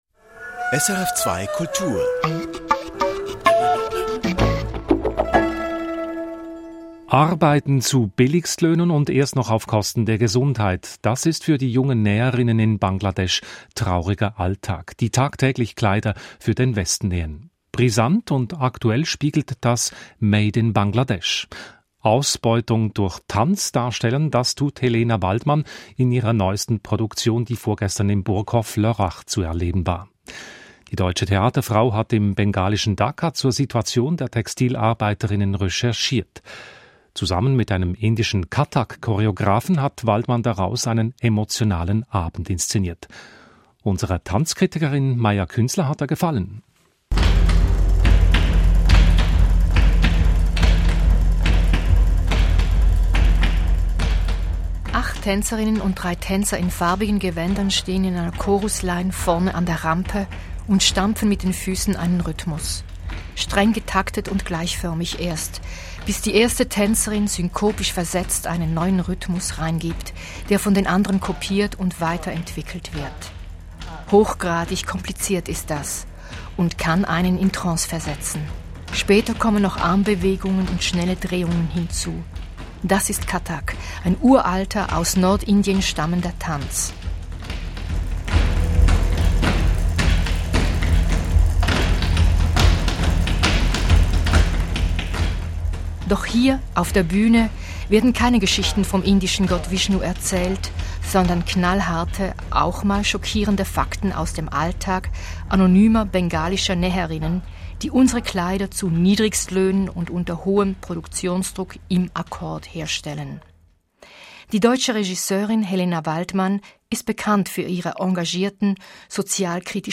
Arbeitskampf auf der Tanzbühne Interview